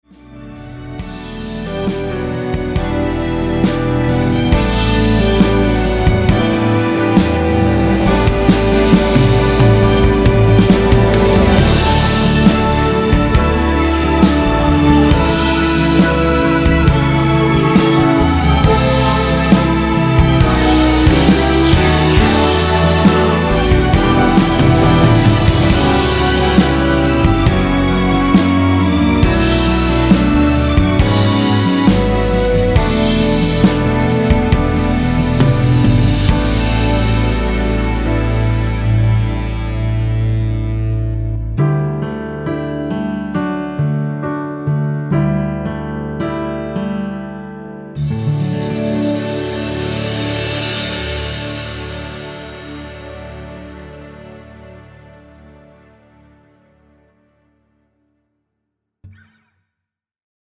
이곡은 코러스가 마지막 한부분만 나와서 그부분만 제작이 되었습니다.(미리듣기 확인)
원키에서(-2)내린 코러스 포함된 MR입니다.
앞부분30초, 뒷부분30초씩 편집해서 올려 드리고 있습니다.
중간에 음이 끈어지고 다시 나오는 이유는